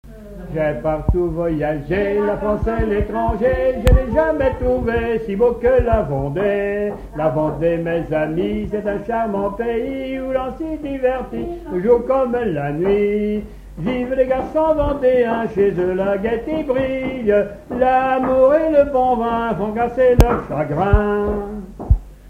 Moutiers-sur-le-Lay
gestuel : danse
Pièce musicale inédite